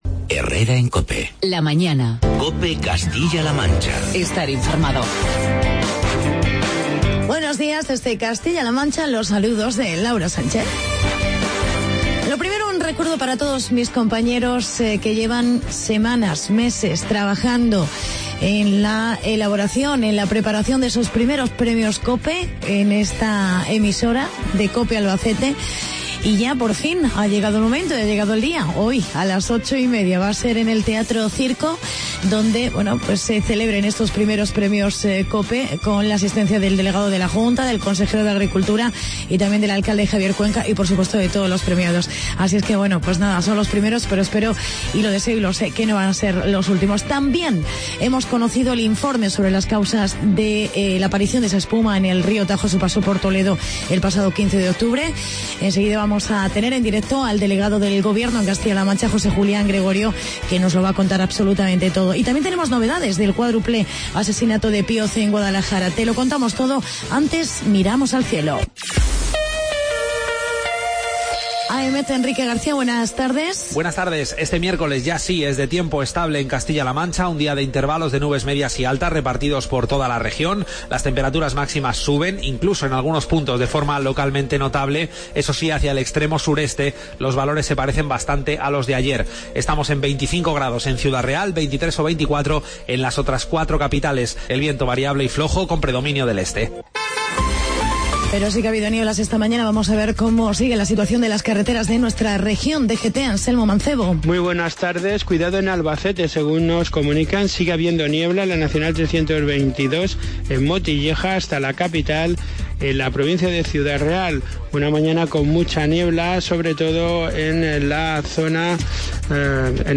Actualidad y entrevista con el delegado del Gobierno en CLM, José Julián Gregorio sobre el Informe de la CHT de las causas de los vertidos al río Tajo.